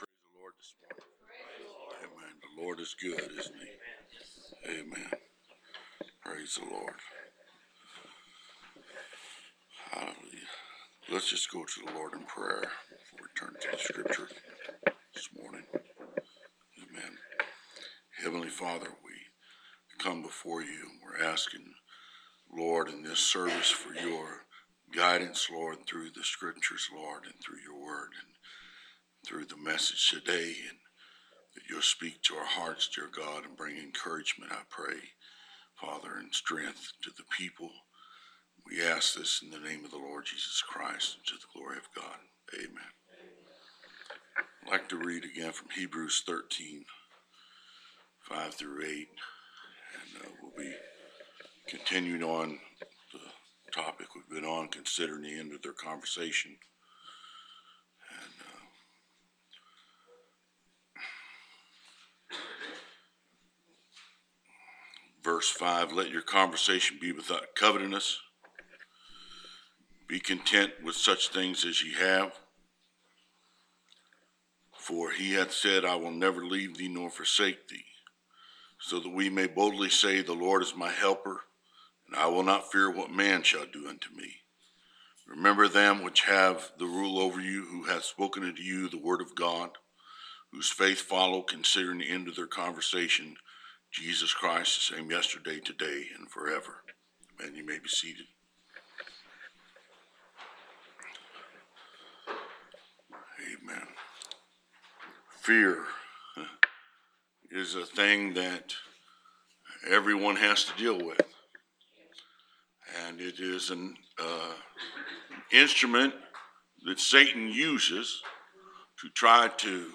Preached March 27, 2022